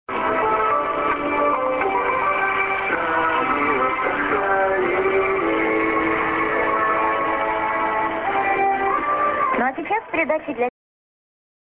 ->ID(man:song)->　USB R.Sakharinsk(Radio Rossii)